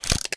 shutter.ogg